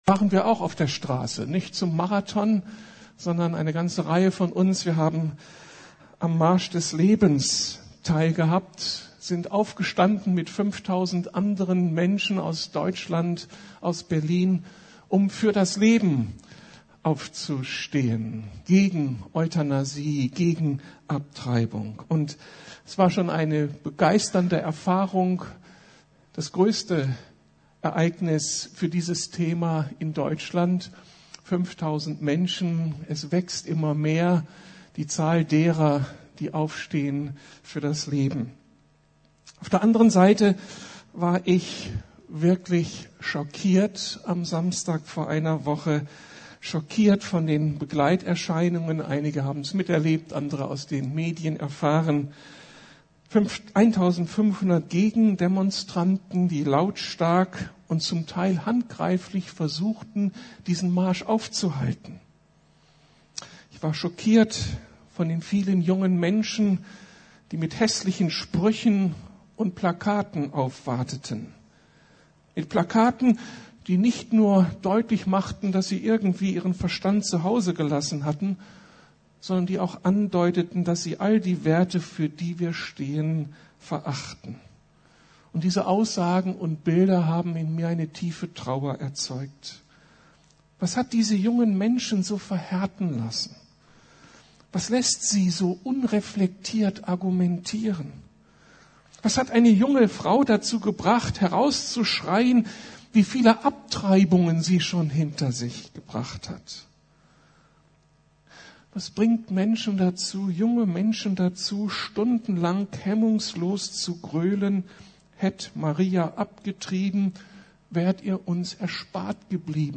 Damit unsere Kinder Zukunft haben! ~ Predigten der LUKAS GEMEINDE Podcast